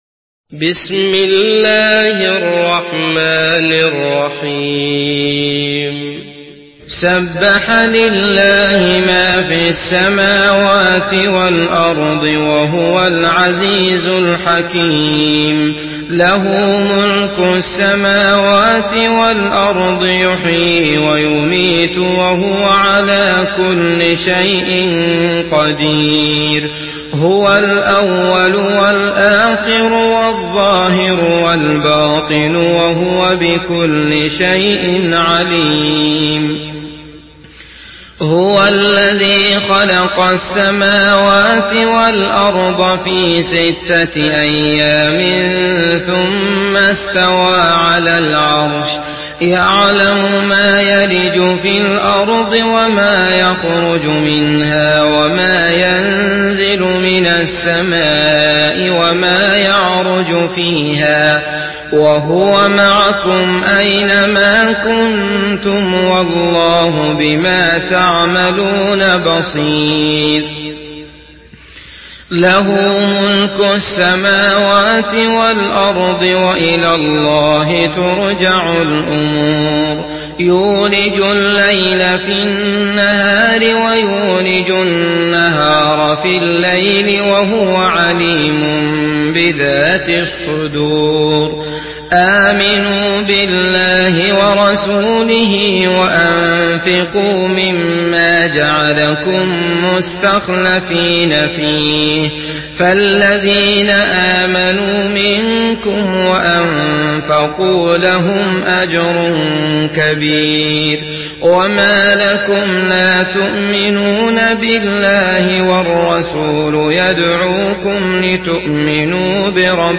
Surah Al-Hadid MP3 in the Voice of Abdullah Al Matrood in Hafs Narration
Murattal